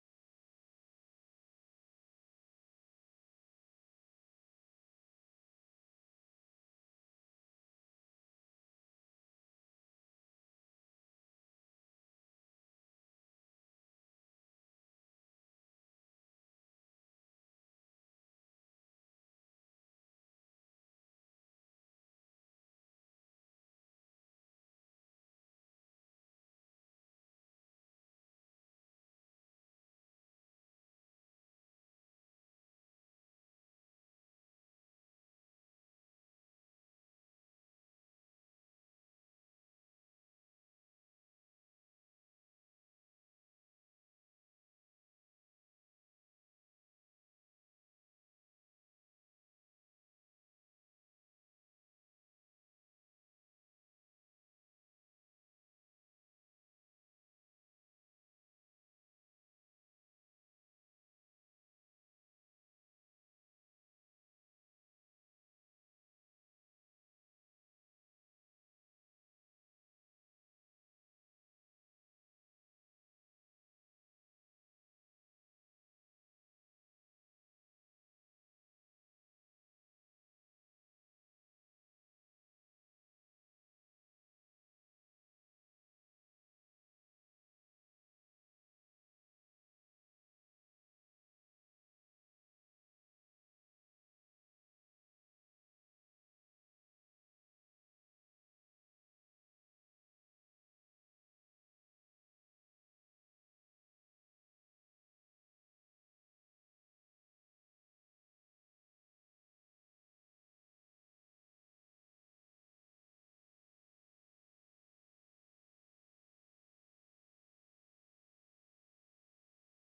November 28, 2021 (Morning Worship)